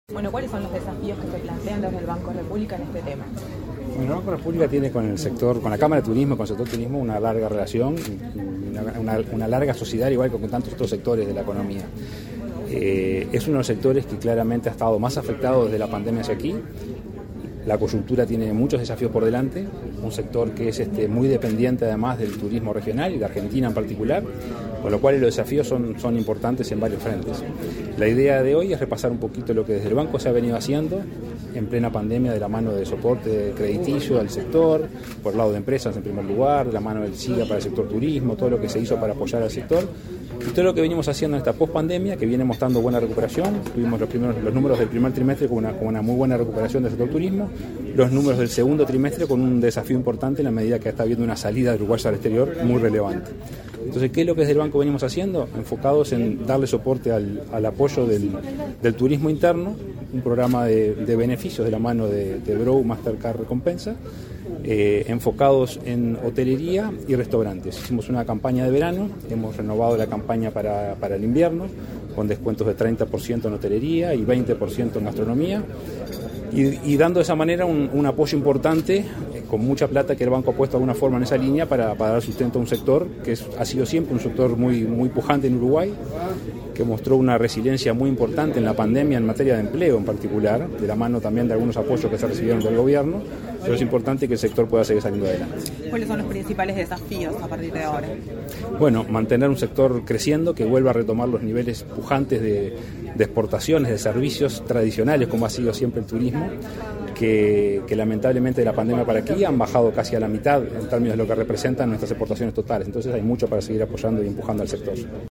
Entrevista al presidente del Banco República, Salvador Ferrer